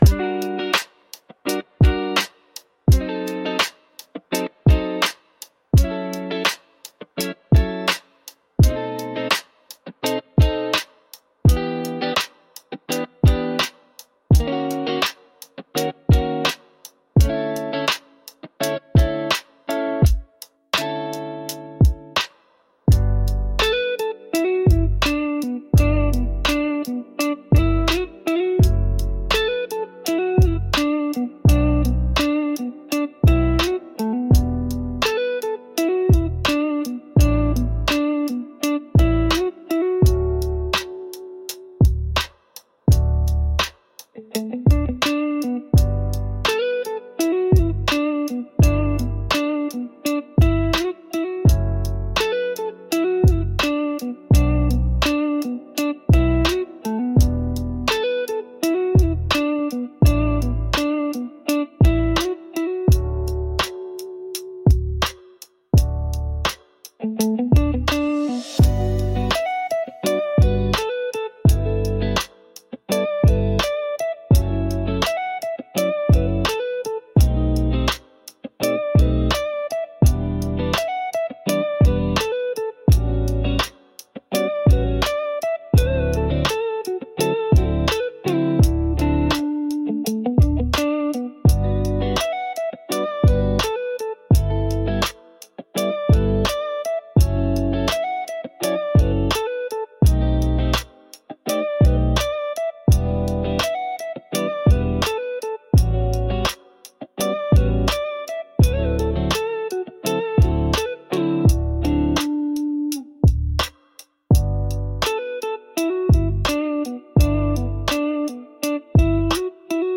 D Major – 84 BPM
Pop
Rnb